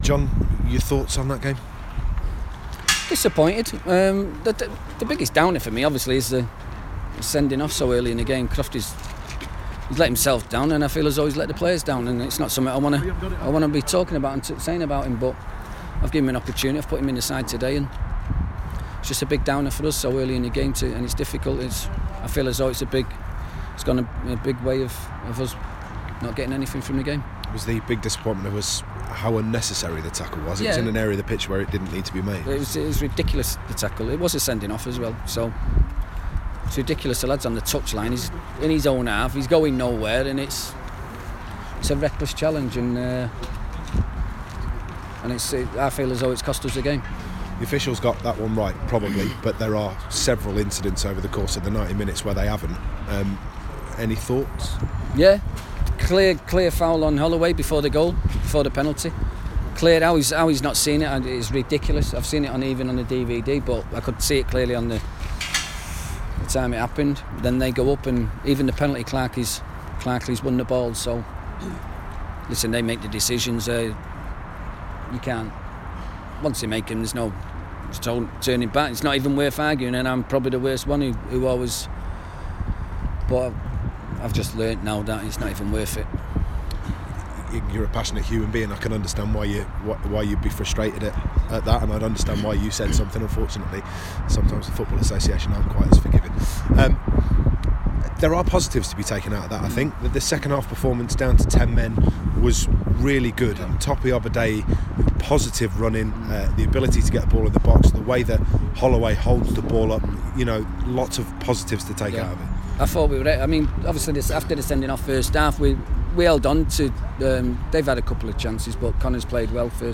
Oldham Athletic manager John Sheridan talks to the press after the trip to Walsall ended in a 2-0 defeat.